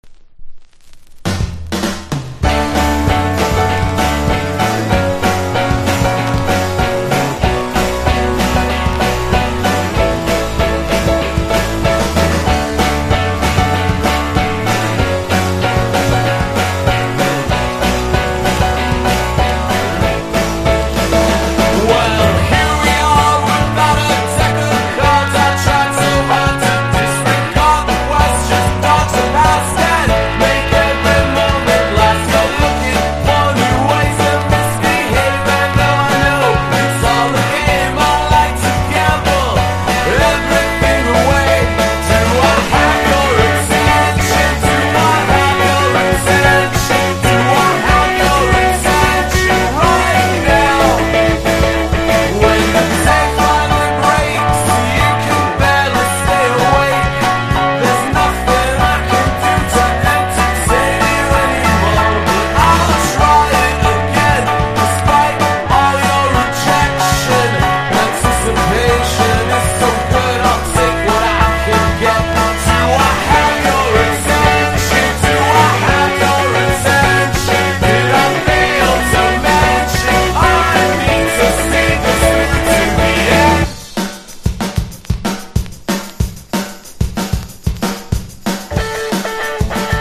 1. 00S ROCK >
NEO ACOUSTIC / GUITAR POP